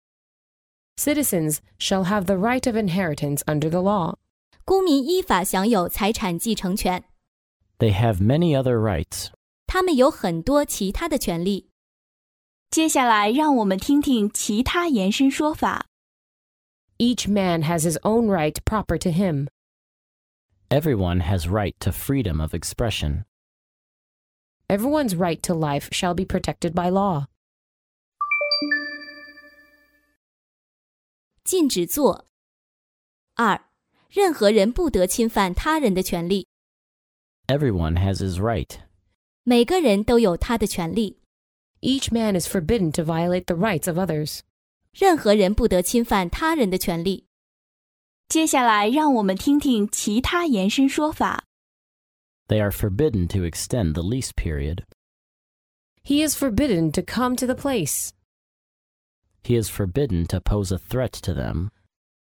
真人发音的朗读版帮助网友熟读熟记，在工作中举一反三，游刃有余。